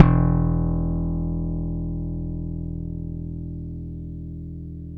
Index of /90_sSampleCDs/Roland - Rhythm Section/BS _Rock Bass/BS _Stretch Bass
BS  POP JP 1.wav